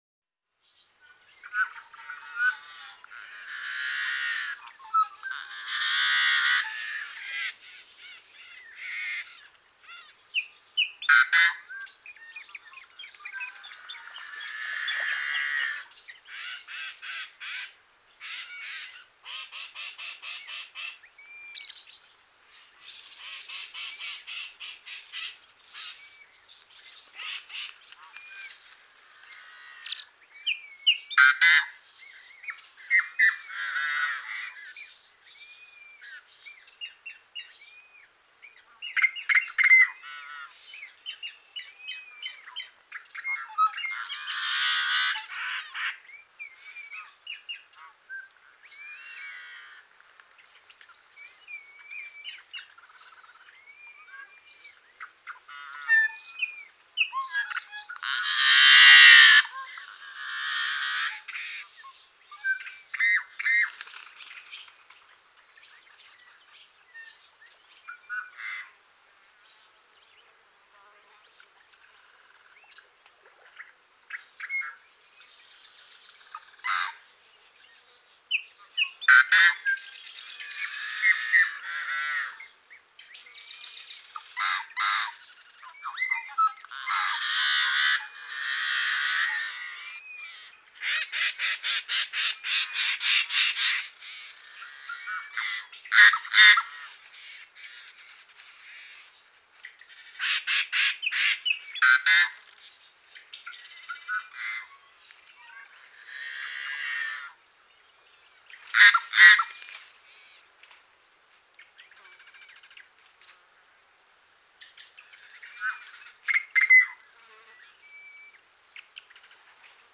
Blackbird, Yellow-headed Chirps, Warbles, Tweets, Squawks. Several Different Calls From Various Birds In The Foreground And Background. Chirps, Warbles, Tweets And Squawks Are All Heard.